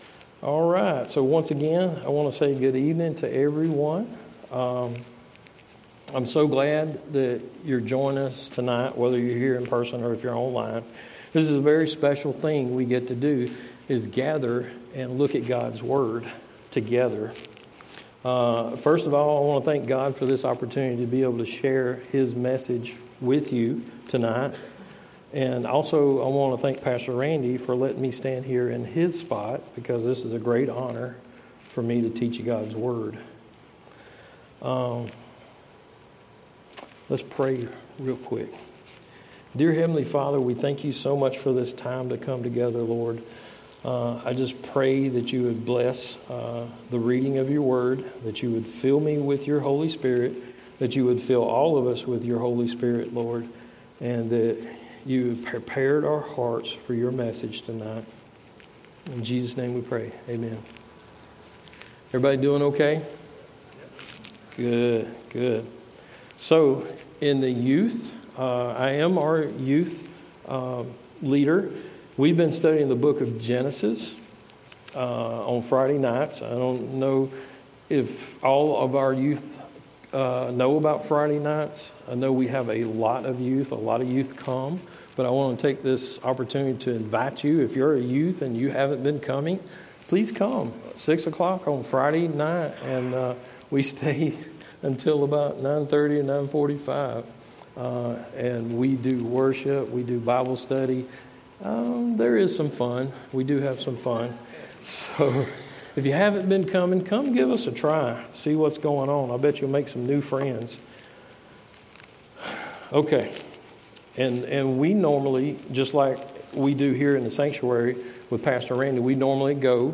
A message from the series "Wednesday Evening."